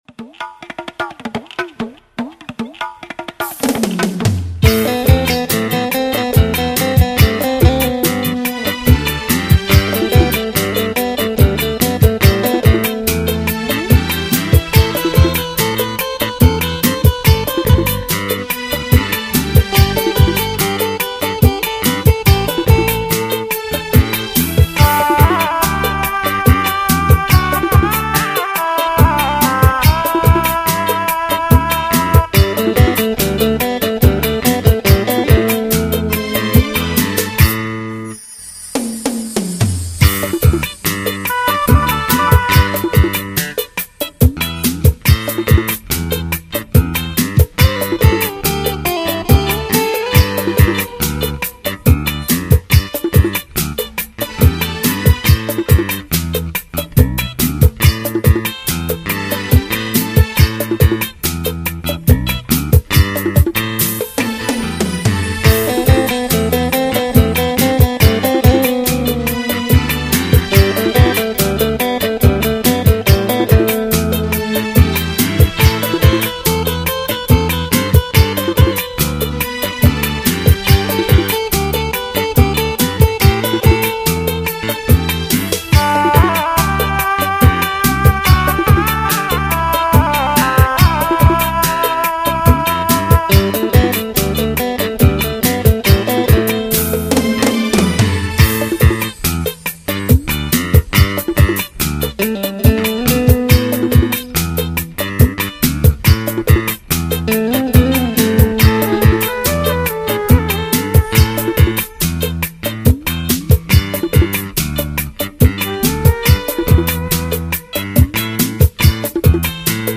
No Voice Karaoke Track Mp3 Download